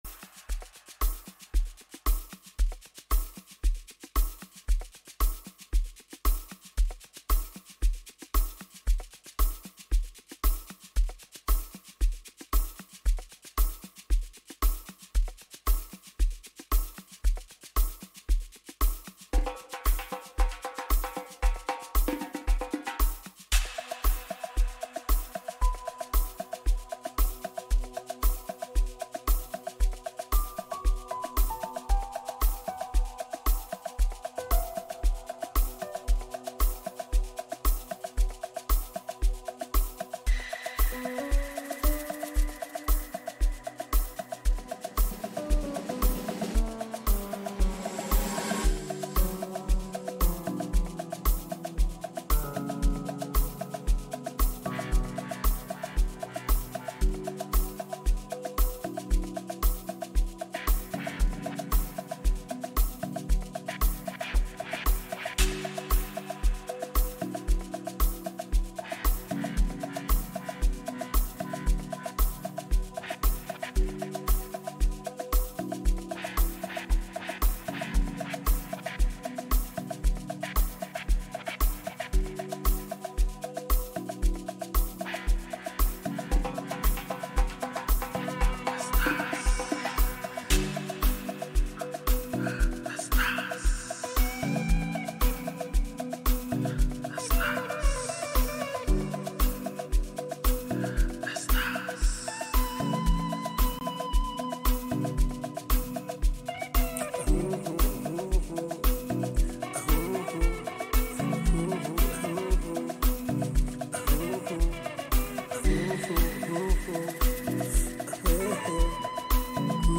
S African talented music producers